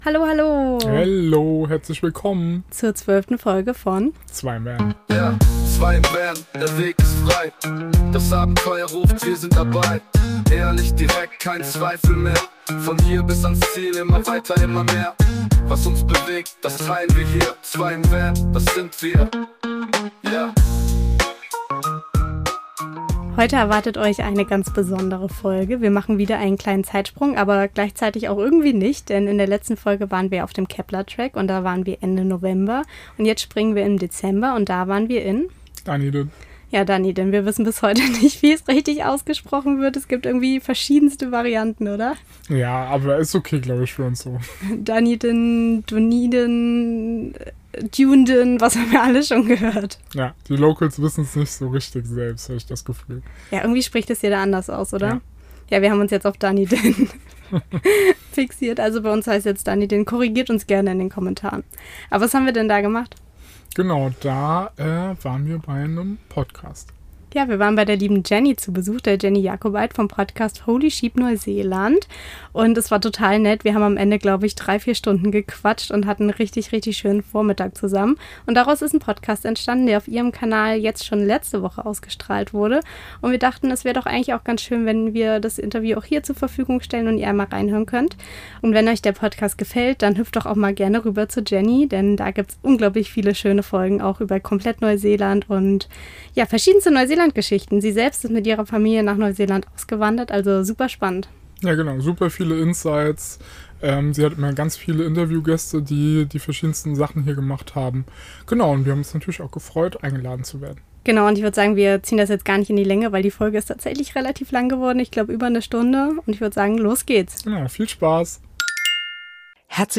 Wir schlüpfen in die Rolle der Interviewgäste und beantworten Fragen, die wir hier bisher nicht angesprochen haben. Wie fühlt es sich an, mit dem eigenen Camper am anderen Ende der Welt zu leben? Warum ist Struktur im Vanlife so wichtig? Und welche Rolle spielt Social Media bei unserem Abenteuer?